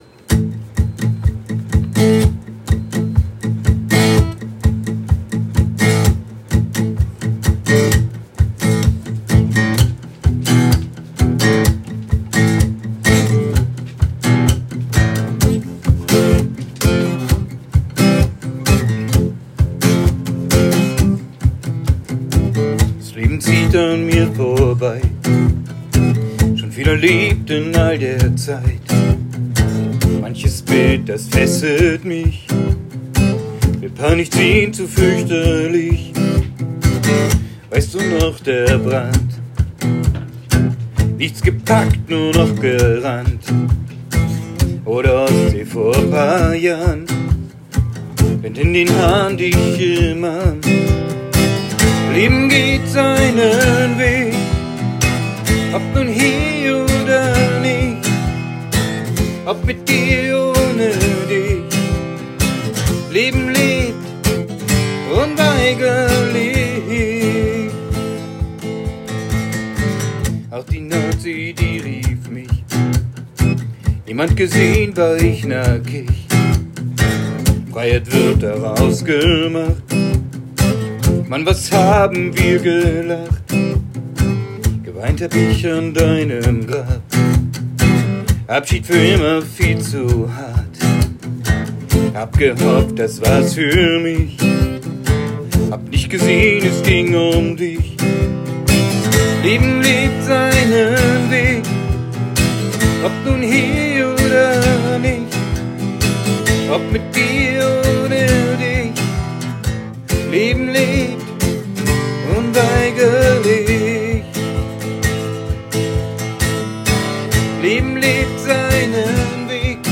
Eine erste Handyaufnahme direkt vom ersten Abend ist hier zu hören.